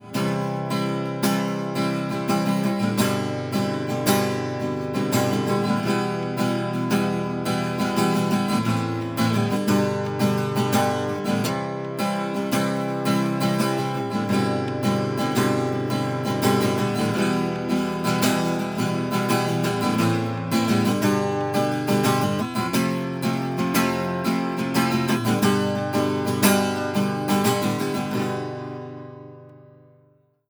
アコースティク・ギター
オン・マイクではサウンド・ホールから30cm程度の距離に、オフ・マイクでは1m程度の距離にセットしています。
ストローク/オン・マイク
acousticG_Cutting_on.wav